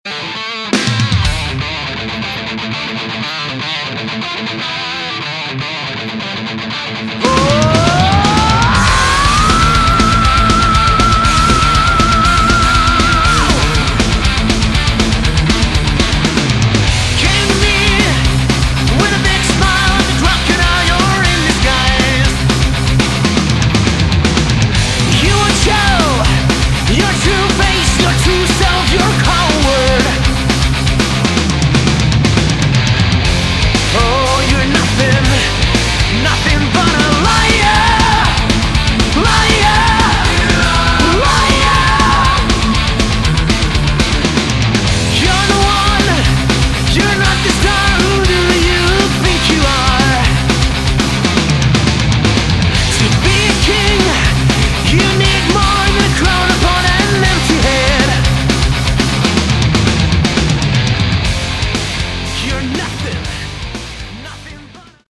Category: Melodic Metal
lead vocals
guitars, backing vocals
bass, backing vocals
drums